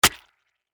JavelinPickUp.mp3